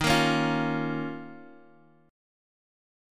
D#7sus2 chord